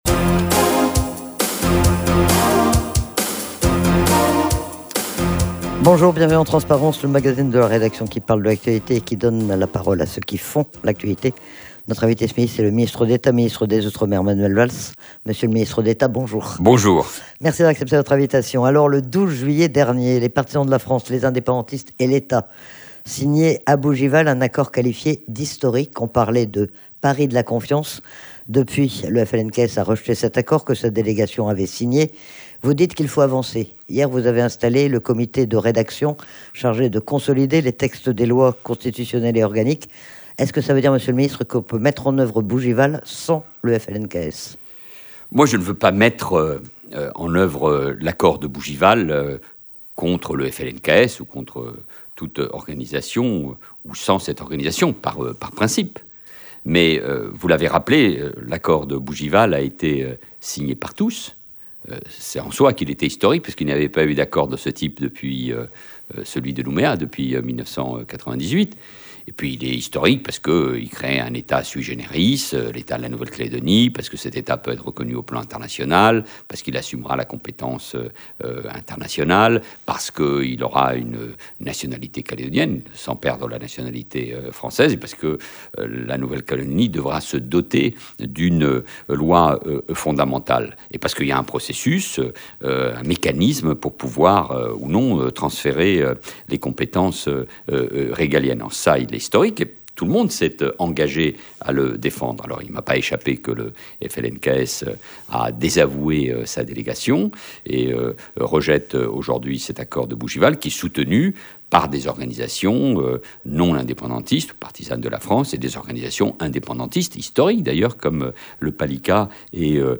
C'est Manuel Valls, le ministre d'Etat, ministre des outremer qui était notre invité ce midi. Il est notamment revenu sur l'accord de Bougival, sur sa mise en œuvre sans le FLNKS et sur le calendrier très contraint que le ministre a annoncé hier en installant le comité de rédaction.